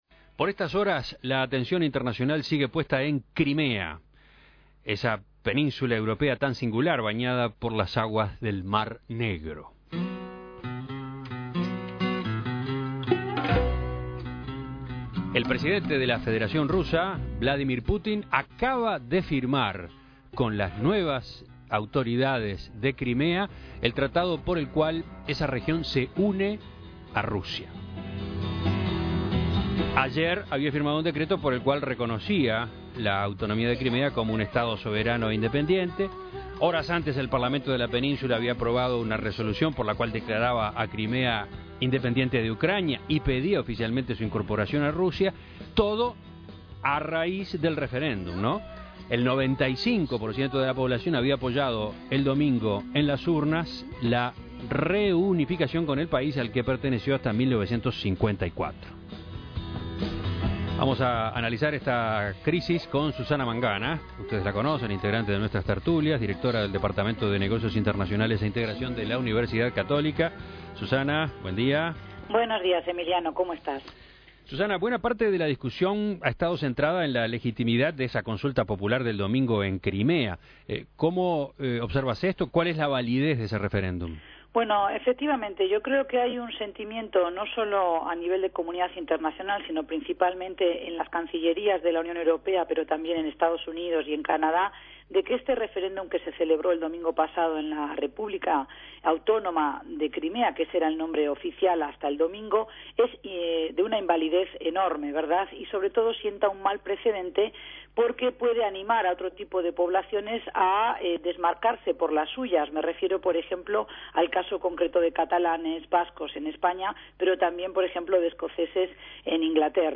Entrevistas Por estas horas, la atención internacional sigue puesta en Ucrania, concretamente en Crimea.